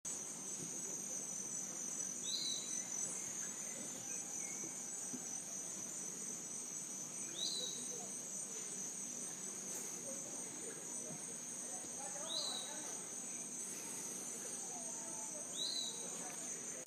Bem-te-vi-pirata (Legatus leucophaius)
Nome em Inglês: Piratic Flycatcher
Detalhada localização: Selva Iryapú (600 hectáreas)
Condição: Selvagem
Certeza: Gravado Vocal